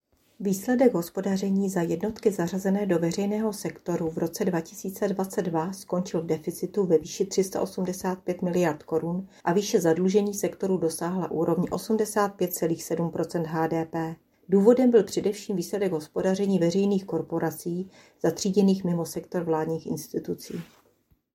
Vyjádření